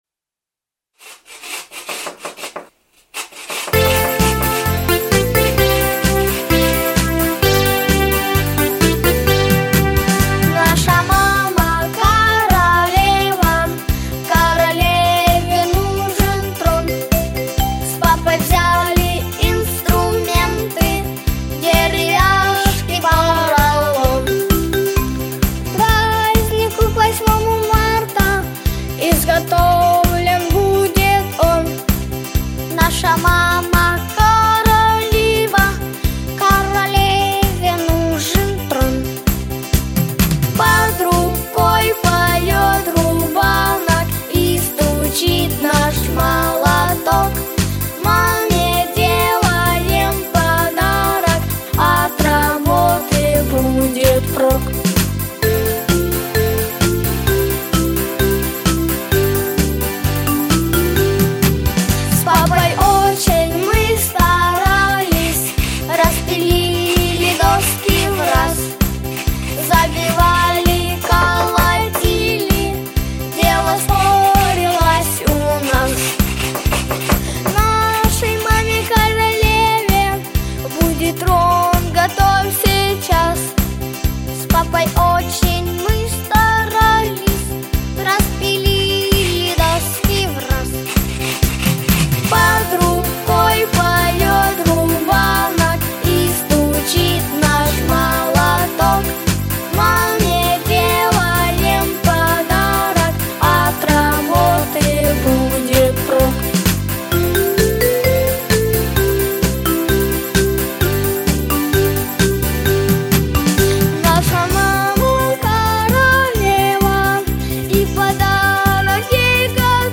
• Категория: Детские песни / Песни про маму